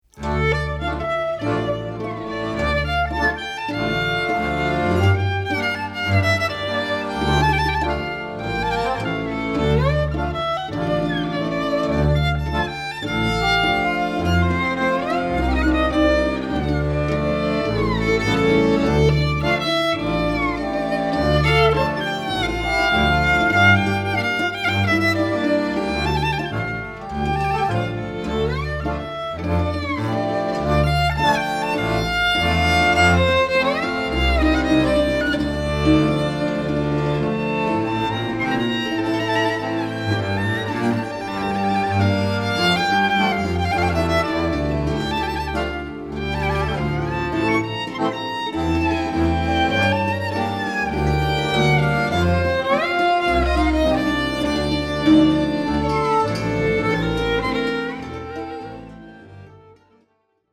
Genres: Klezmer, Jazz, World.
clarinet
sound like traditional Klezmer pieces